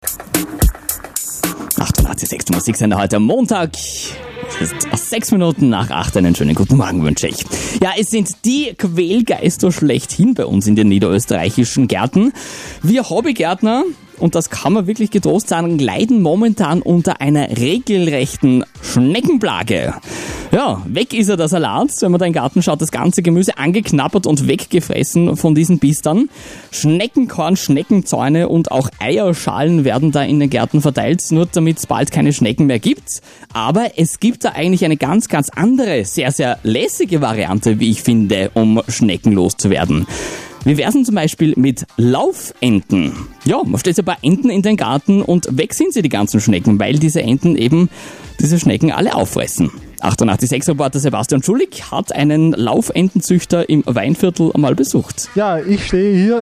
Juli 2013 -  Reportage �ber Laufenten auf Radio 88,6